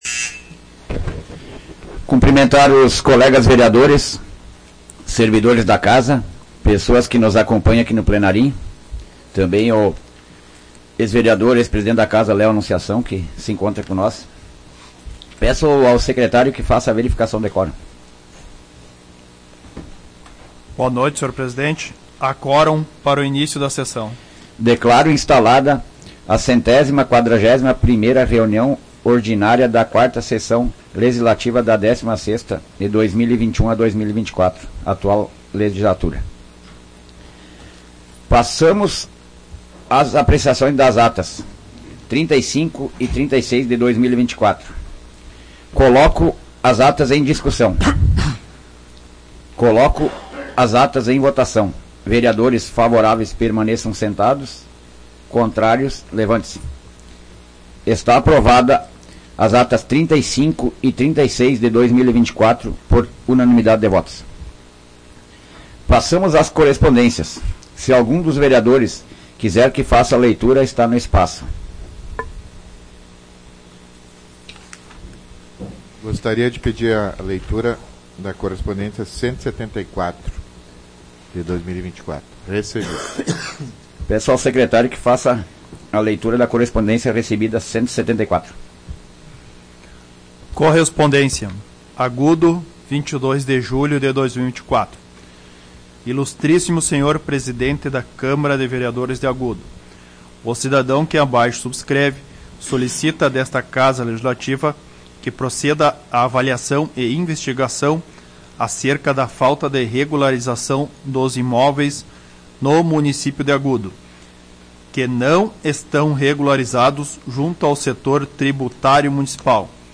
Áudio da 141ª Sessão Plenária Ordinária da 16ª Legislatura, de 22 de julho de 2024